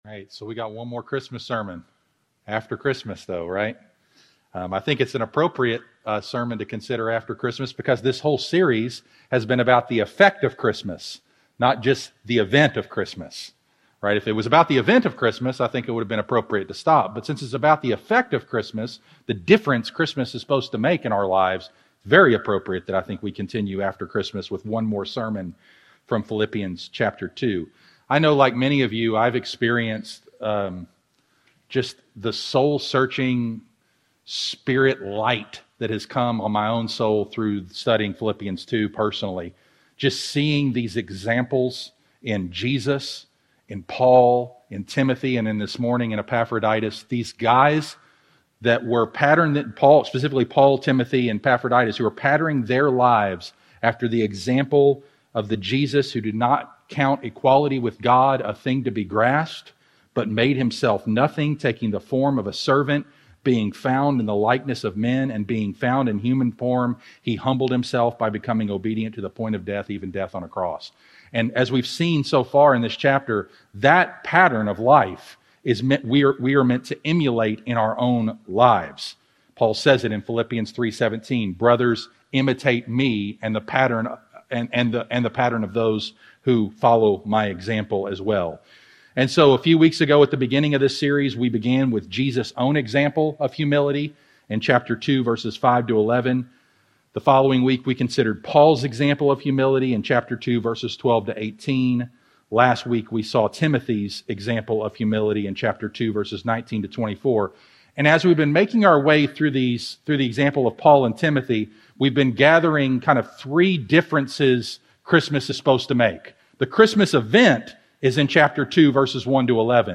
Sermons
Sermons from Heritage Baptist Church in Owensboro, KY